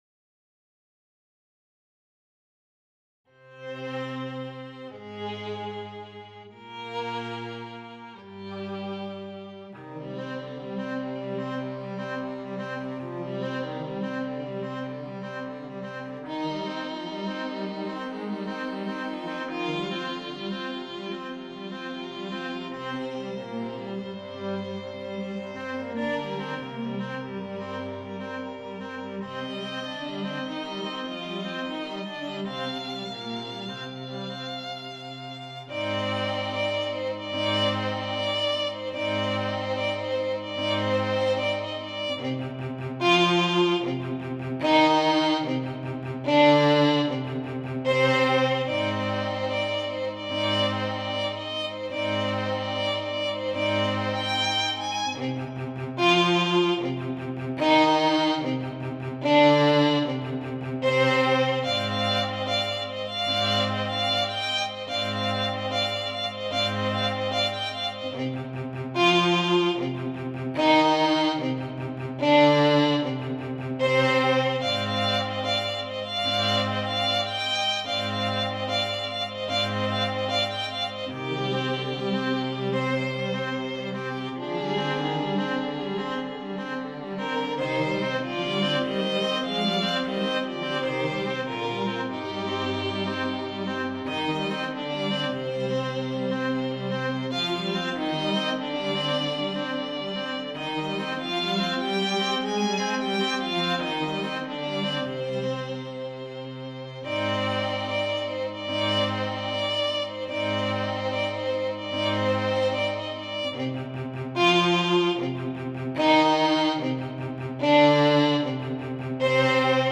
para Quarteto de Cordas, com a seguinte instrumentação:
● Violino I
● Violino II
● Viola
● Violoncelo